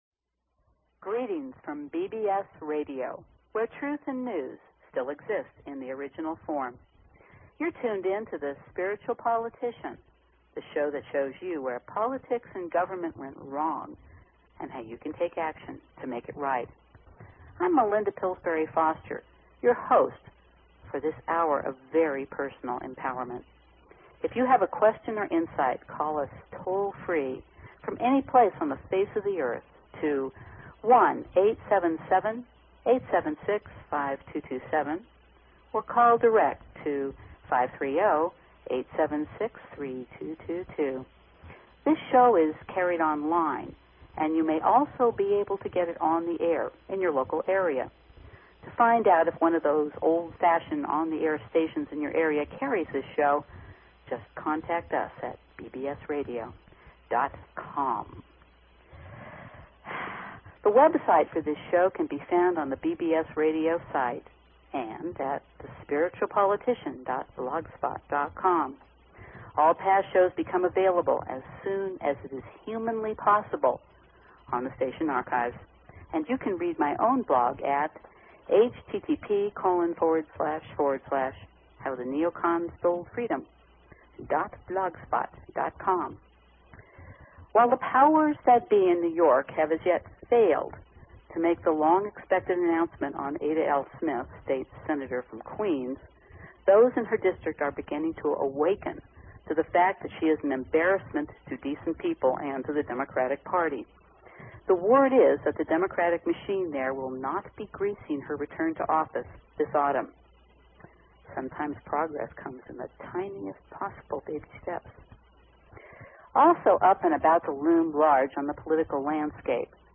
Talk Show Episode, Audio Podcast, Spiritual_Politician and Courtesy of BBS Radio on , show guests , about , categorized as